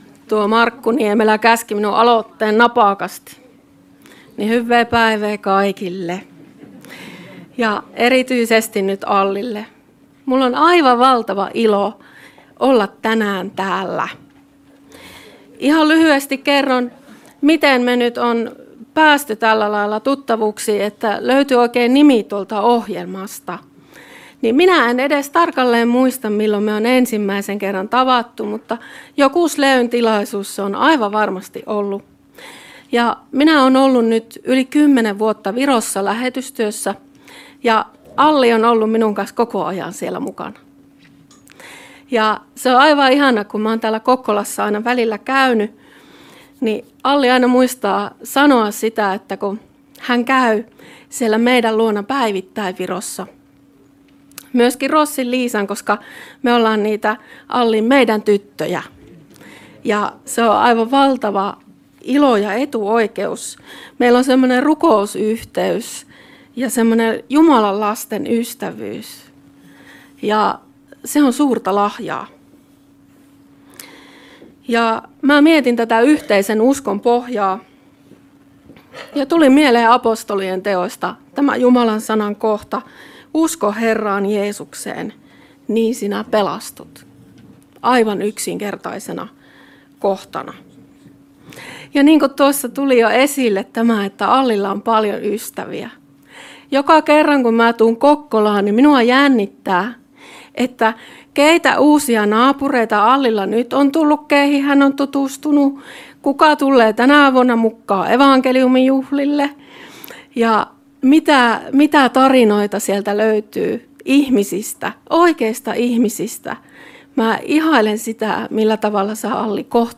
onnittelupuhe Kokkolassa